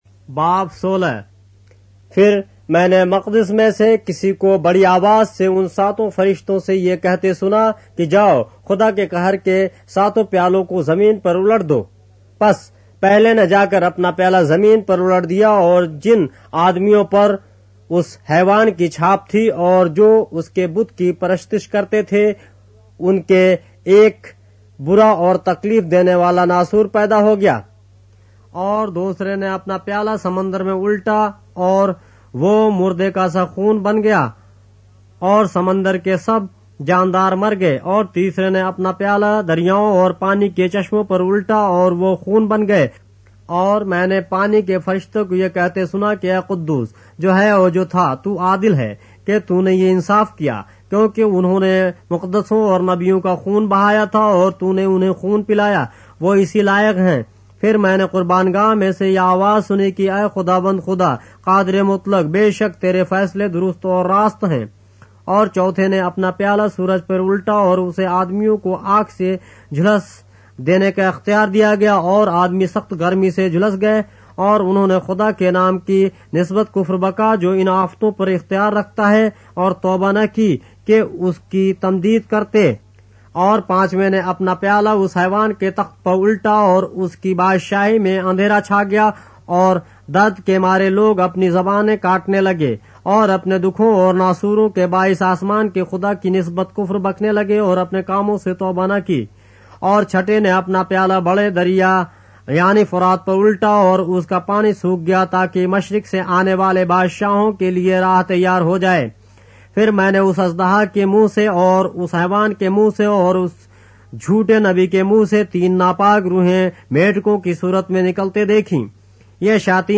اردو بائبل کے باب - آڈیو روایت کے ساتھ - Revelation, chapter 16 of the Holy Bible in Urdu